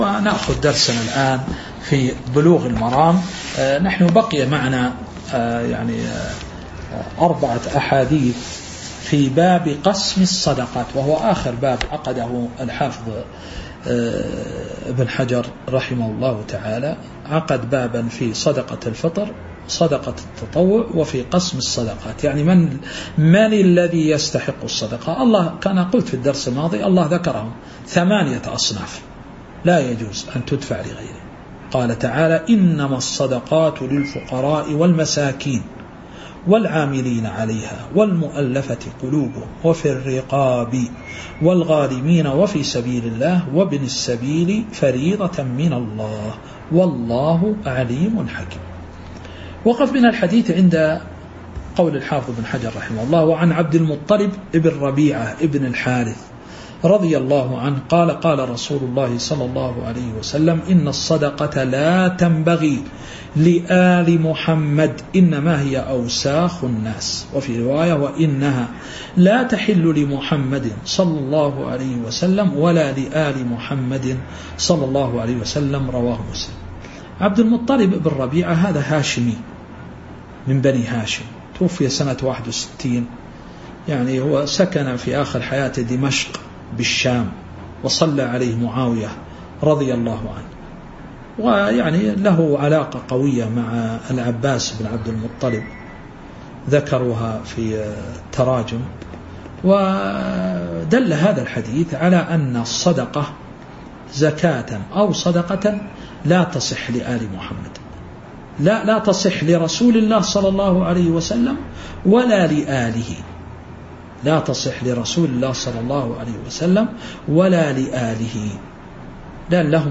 تاريخ النشر ٢٥ شوال ١٤٤٥ هـ المكان: المسجد النبوي الشيخ